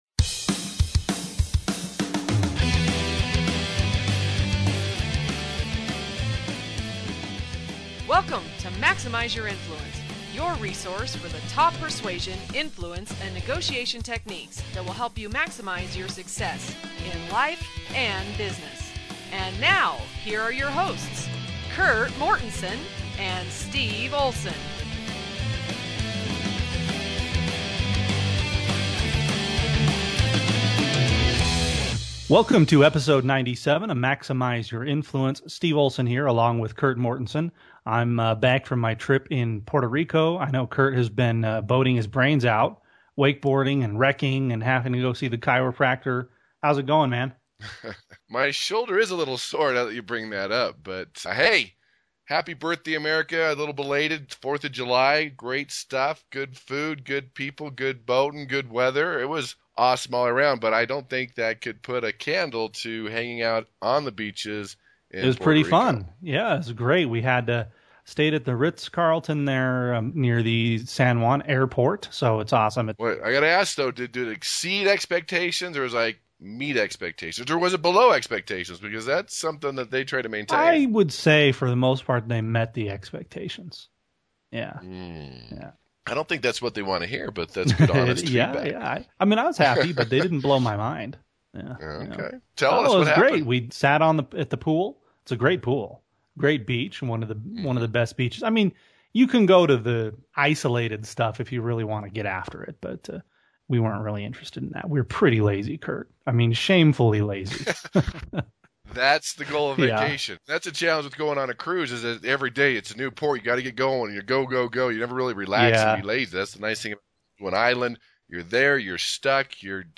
Episode 97 – Interview